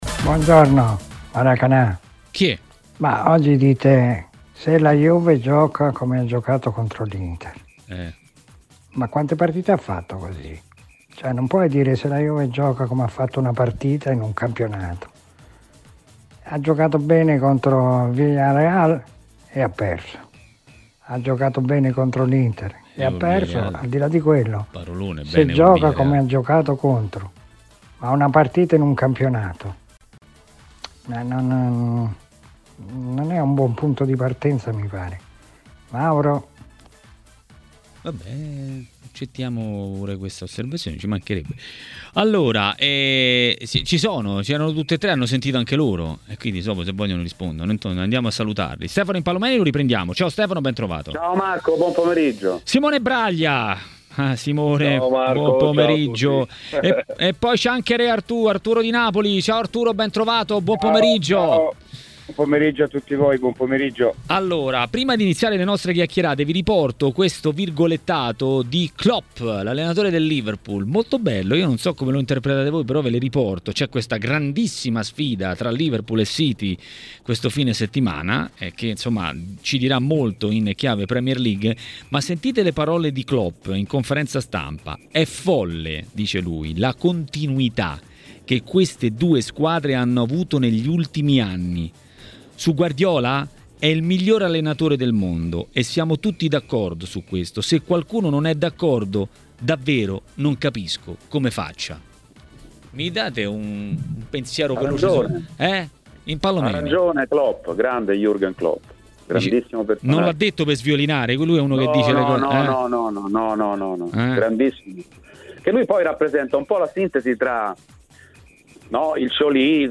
La ripresa del campionato è stato il tema principale a Maracanà, trasmissione di TMW Radio. A dire la sua un ex calciatore come Arturo Di Napoli.